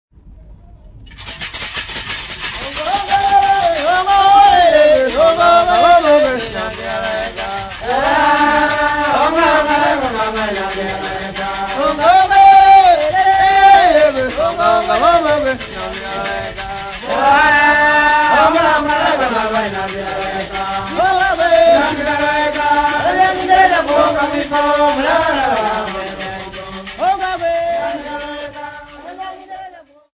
Zaramo boys
Folk Music
Field recordings
Africa Tanzania Dar-es-Salaam f-tz
sound recording-musical
Indigenous music